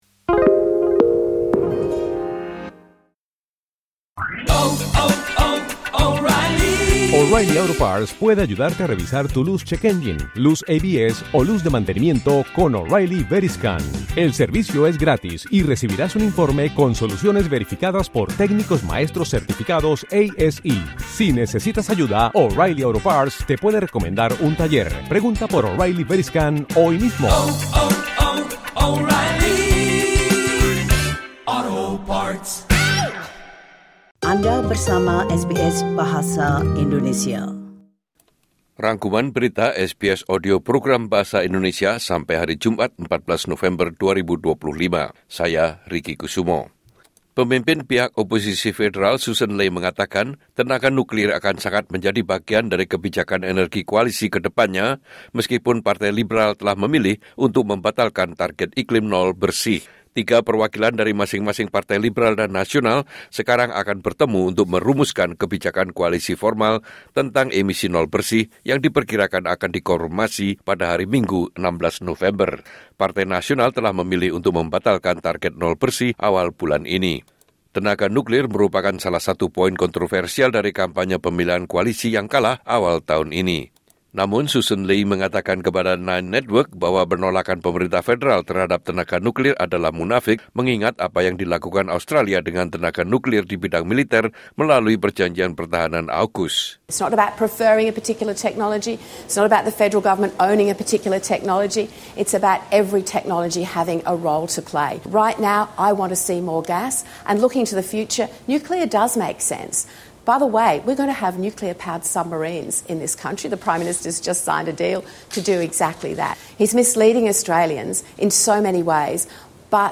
Rangkuman Berita Mingguan SBS Audio Program Bahasa Indonesia - Jumat 14 November 2025